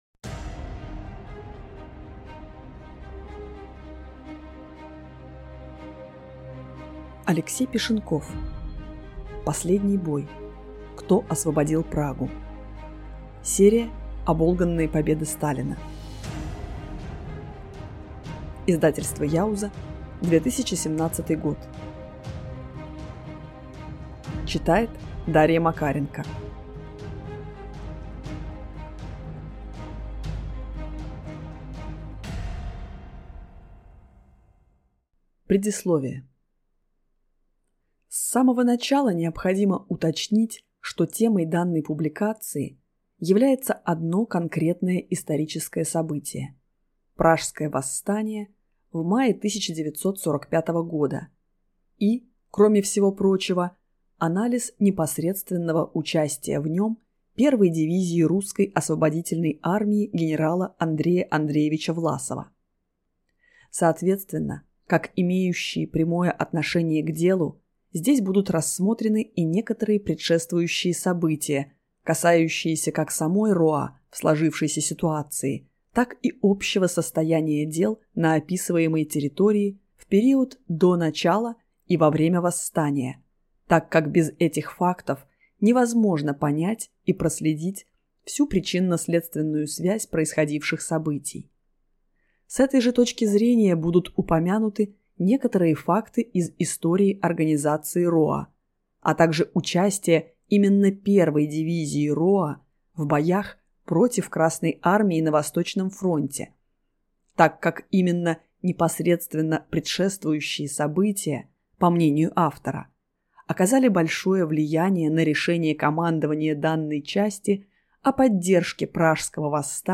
Аудиокнига Последний бой. Кто освободил Прагу?